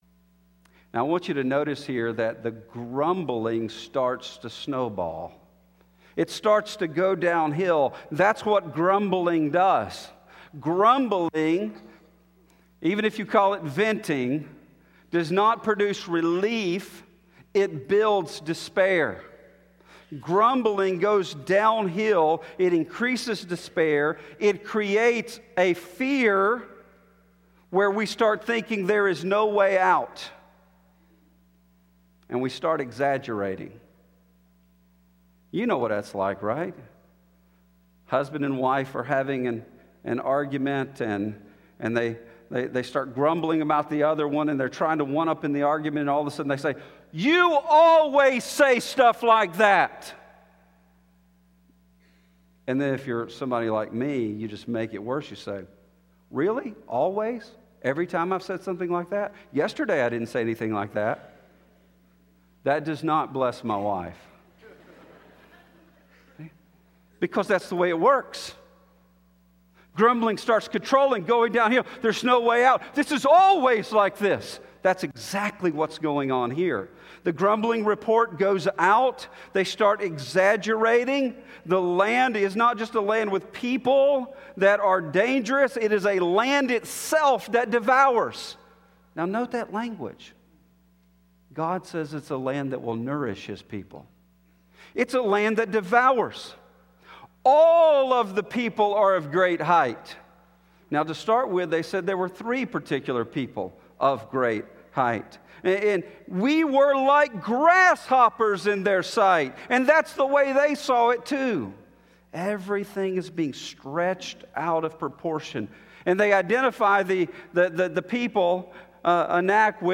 Sermon begins at the 27:42 mark